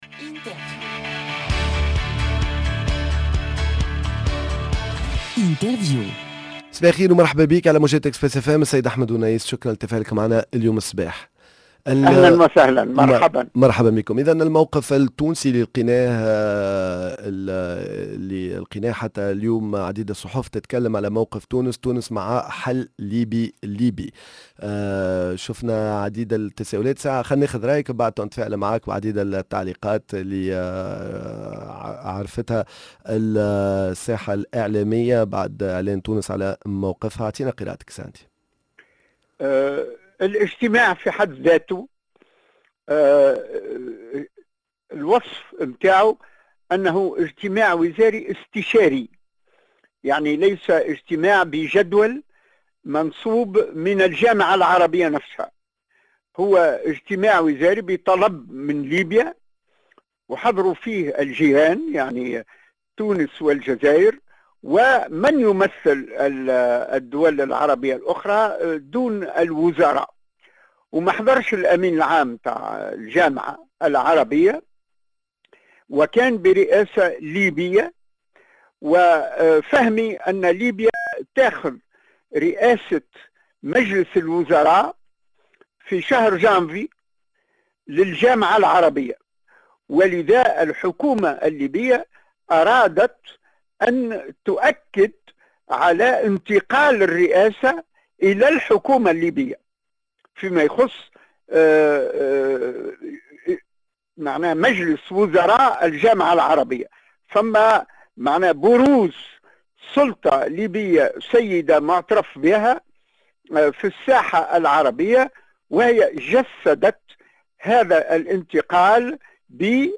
تونس نشارك في الاجتماع التشاوري الدوري لوزراء الخارجية العرب المنعقد بالعاصمة الليبية طرابلس، هل تكون نقطة بداية تطوير العلاقات الديبلوماسية بين تونس و ليبيا ؟! ضيفنا الديبلوماسي الأسبق الأستاذ أحمد ونيس